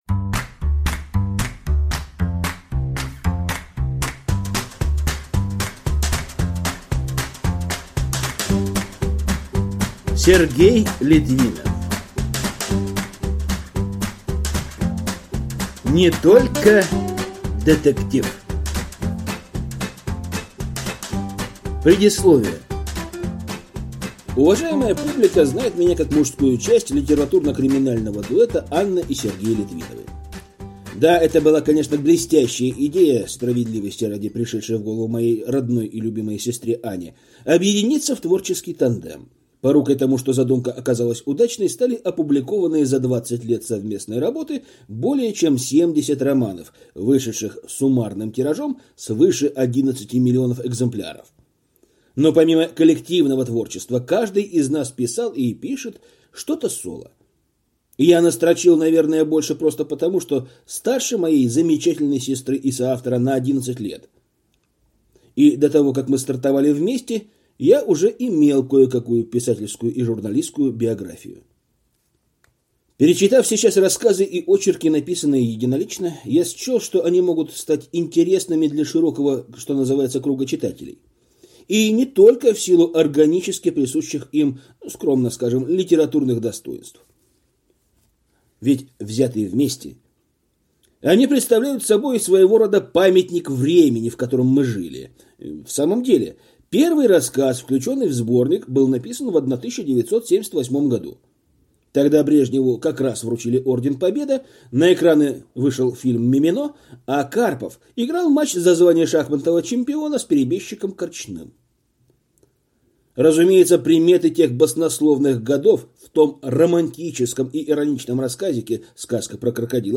Aудиокнига Не только детектив